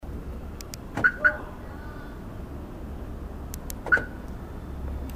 キョン　キョン
外国映画で車のキーを操作する時に、「キョン　キョン」って音がするシーンがあって、カッコイイなぁ～って以前から思っていました。
この機種は音質や鳴る回数など色々設定できて面白いです。
ロック時に２回、アンロック時に１回鳴ります。
siren.mp3